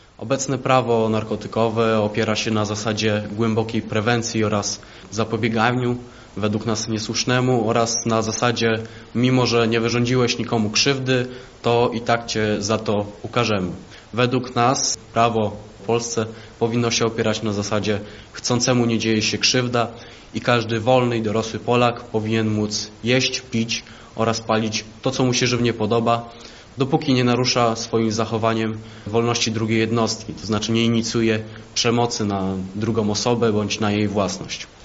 Młodzi korwiniści na konferencji prasowej poruszyli temat legalizacji marihuany na tle innych legalnych w Polsce używek.